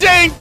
Ooff2.wav